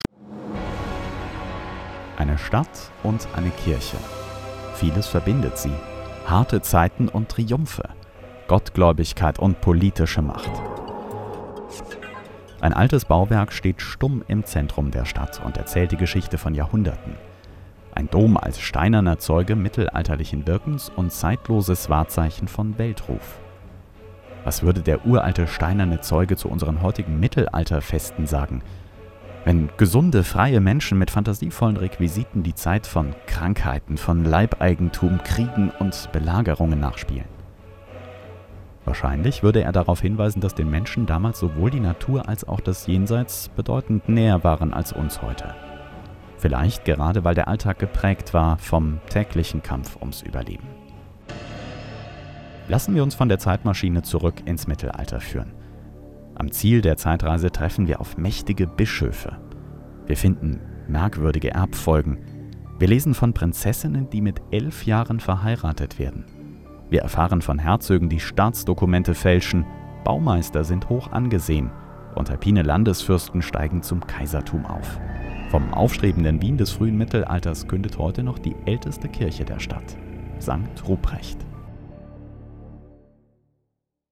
Sprechprobe: Werbung (Muttersprache):
Professional Speaker for News, OFF, E-Learning, Industrial and more...